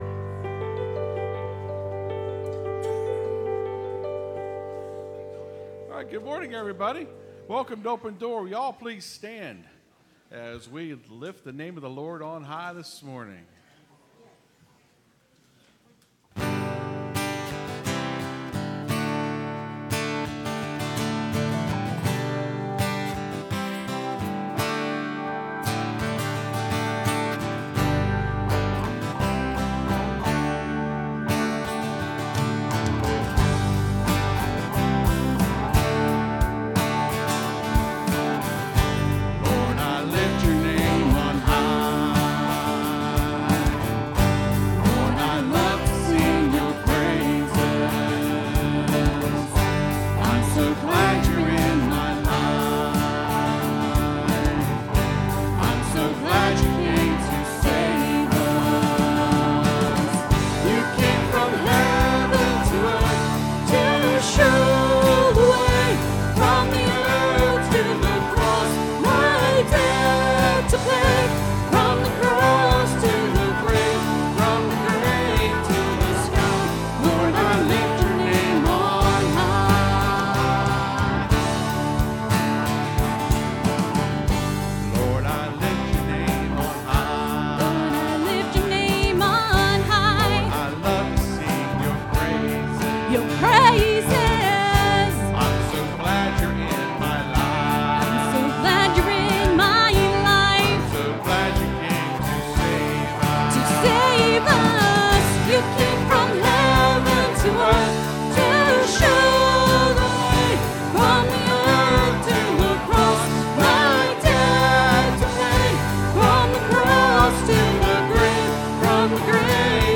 (Sermon starts at 26:00 in the recording).